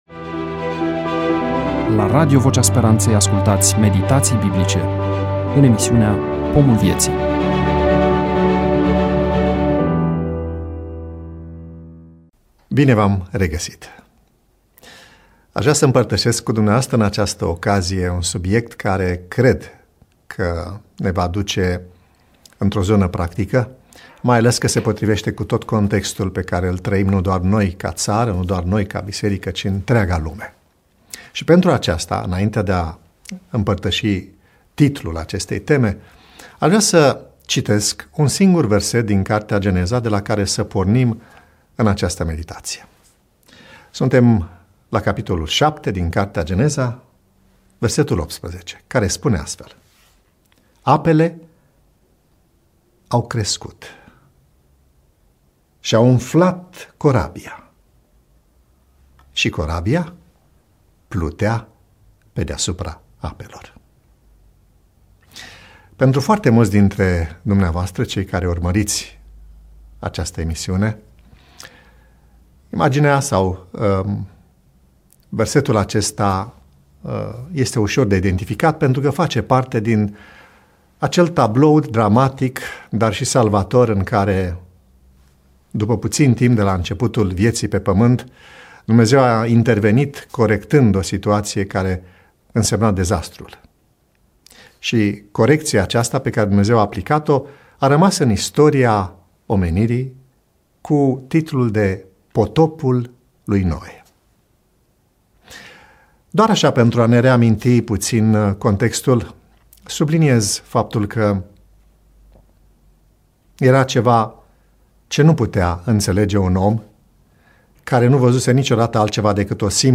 EMISIUNEA: Predică DATA INREGISTRARII: 05.12.2025 VIZUALIZARI: 13